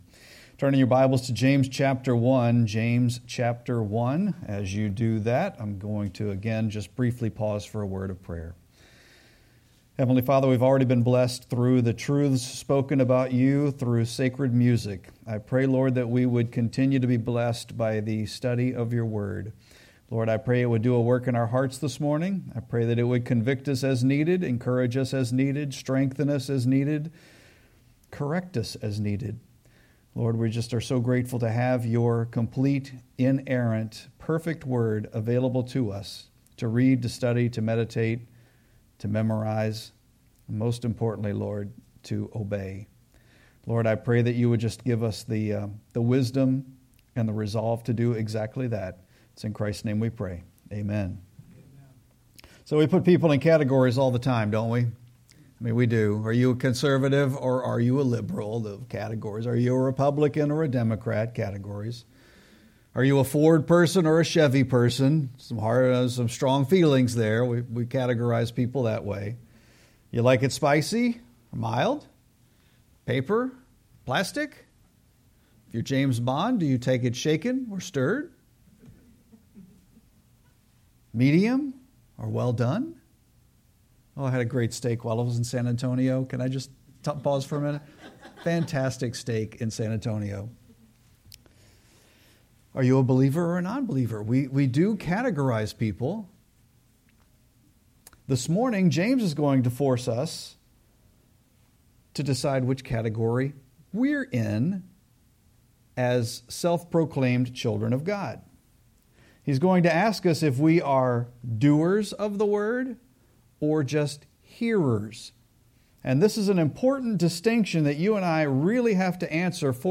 Sermon-6-15-25.mp3